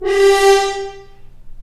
Index of /90_sSampleCDs/Roland L-CD702/VOL-2/BRS_F.Horns FX+/BRS_FHns Mutes